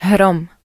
hrom.wav